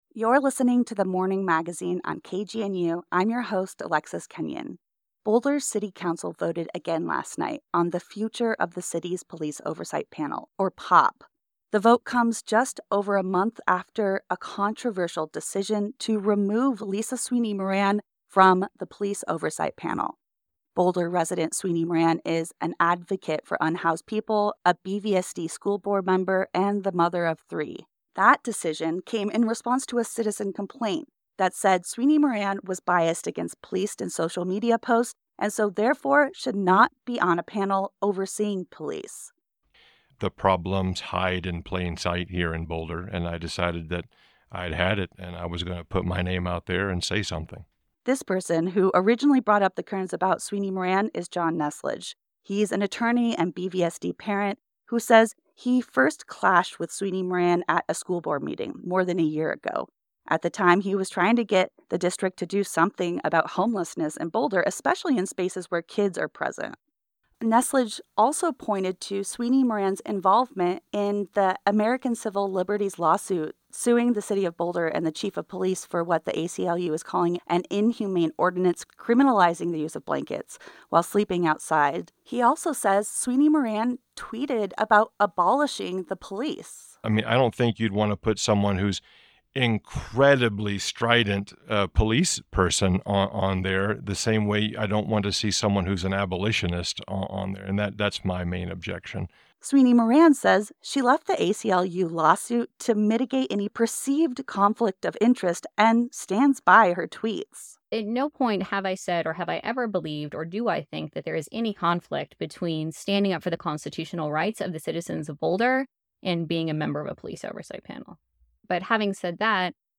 KGNU Broadcast Live On-Air